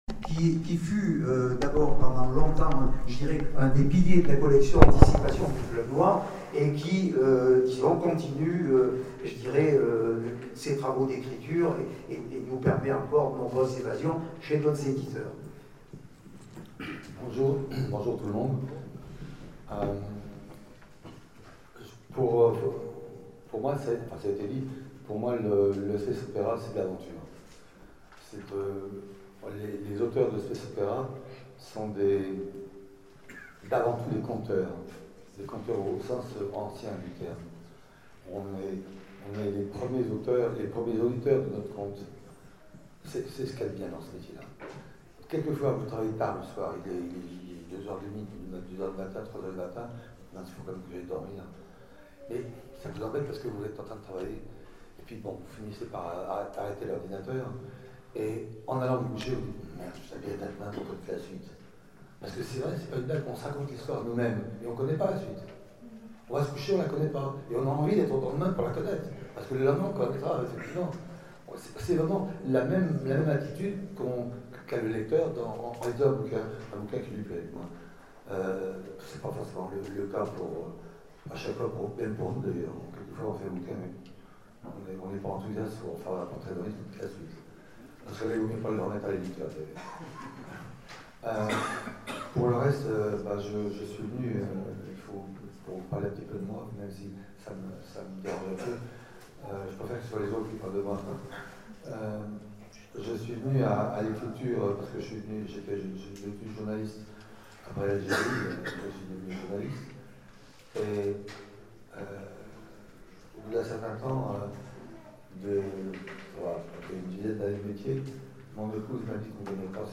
Etonnants Voyageurs 2012 : Conférence Le space opera dans tous ses états - ActuSF - Site sur l'actualité de l'imaginaire
Conférence